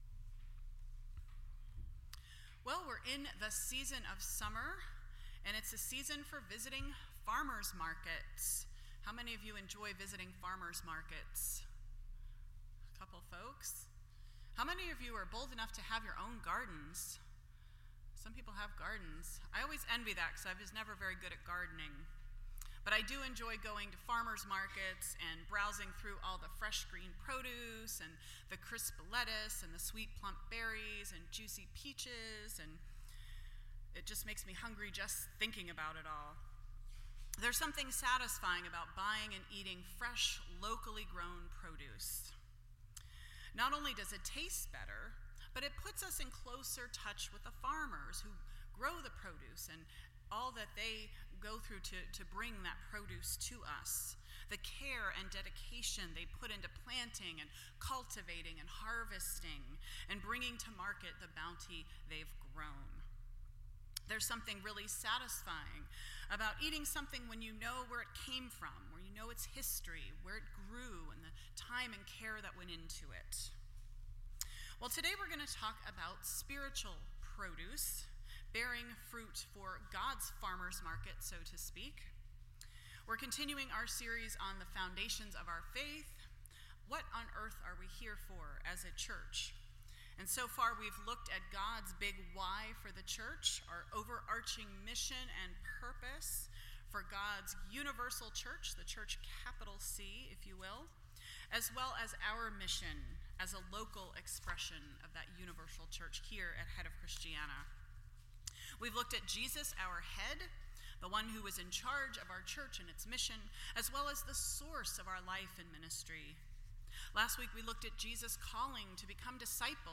Foundations Service Type: Sunday Morning %todo_render% Share This Story